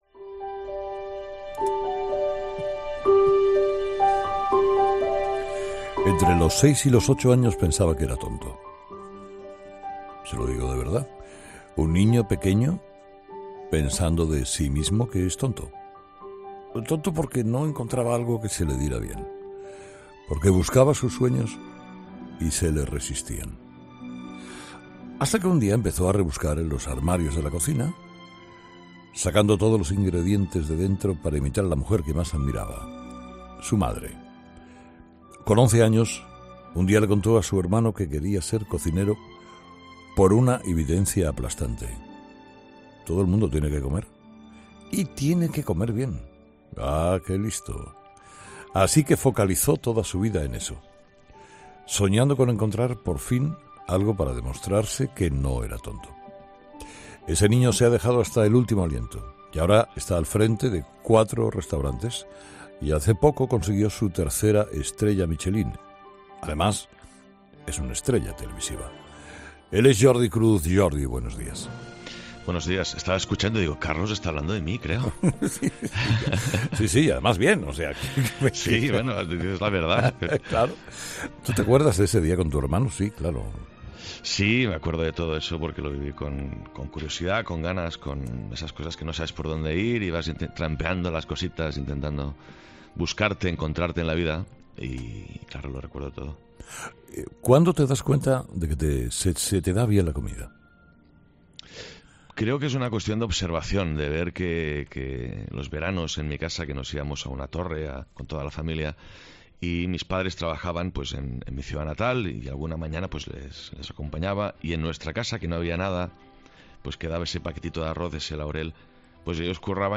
Escucha la entrevista a Jordi Cruz en Herrera en COPE